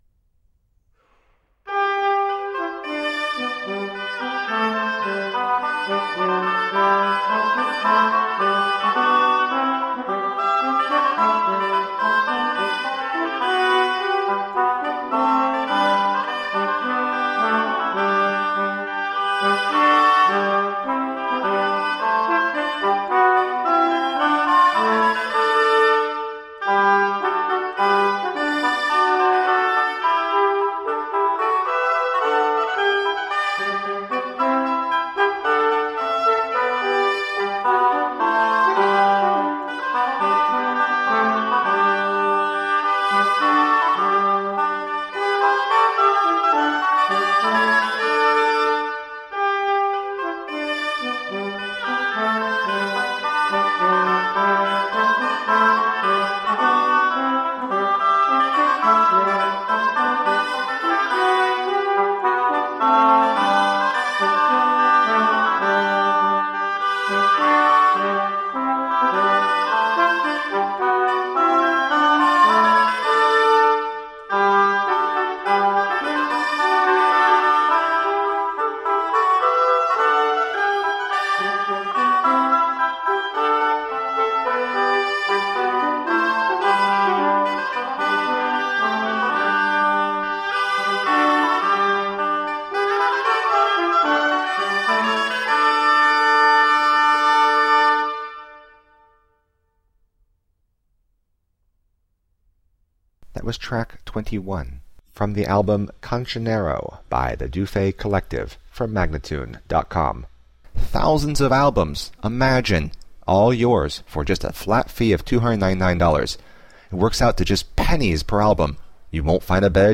Classical, Renaissance, Classical Singing
Flute, Lute, Viola da Gamba